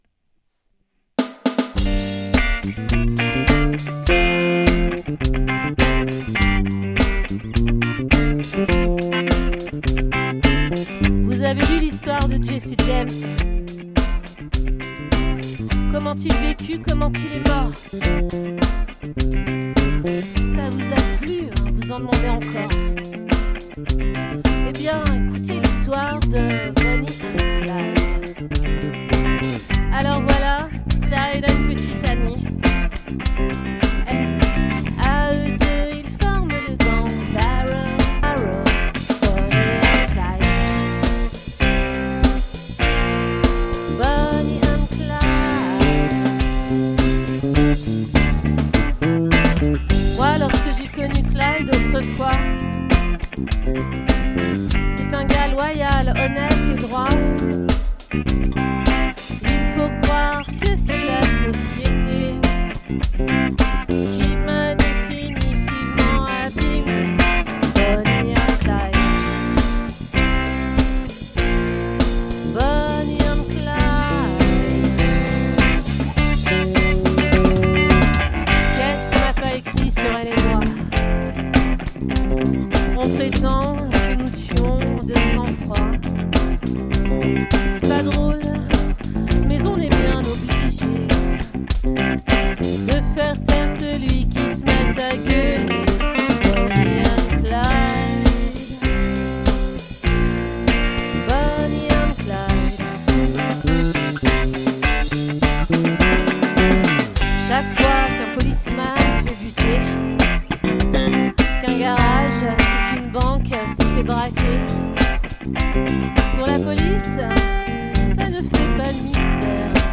🏠 Accueil Repetitions Records_2022_03_16